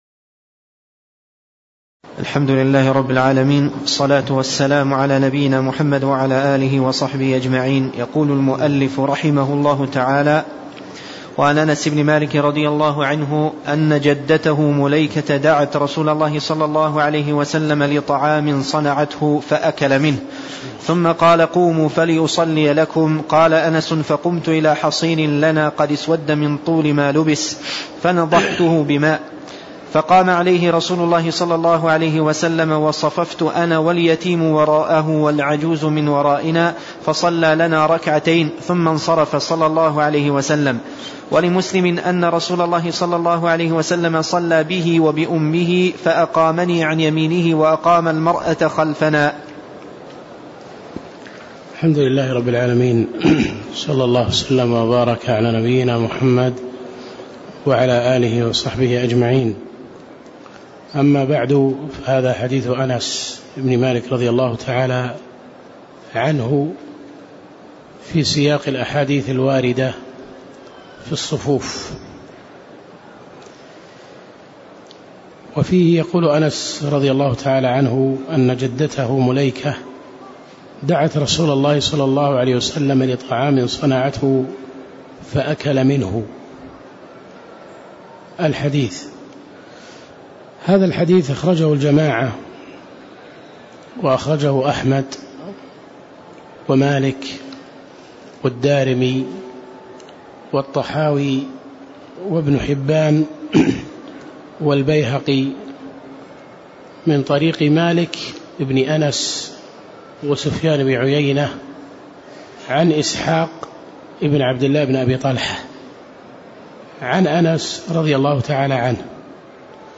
تاريخ النشر ١٥ رجب ١٤٣٦ هـ المكان: المسجد النبوي الشيخ